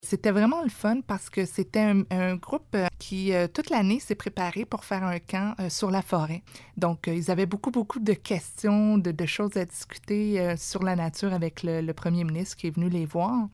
Sophie Chatel donne plus de détails sur la visite :